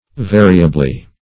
Variably \Va"ri*a*bly\, adv.